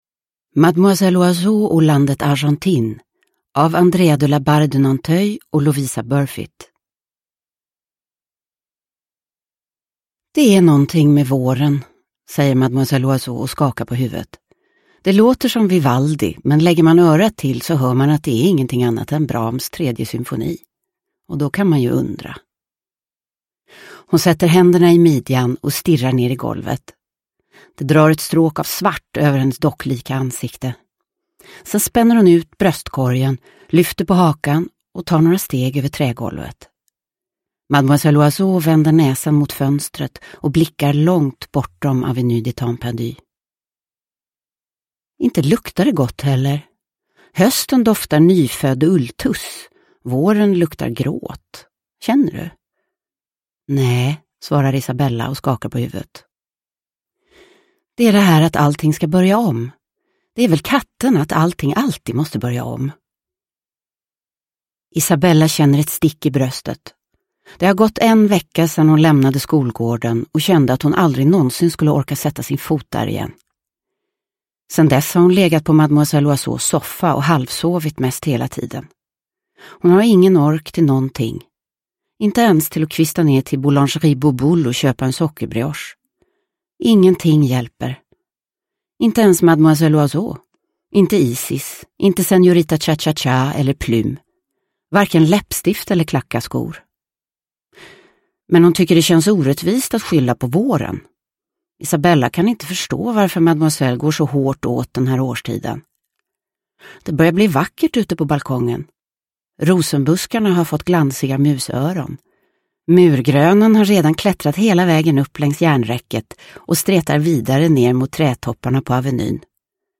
Mademoiselle Oiseau och landet Argentine – Ljudbok – Laddas ner
Uppläsare: Livia Millhagen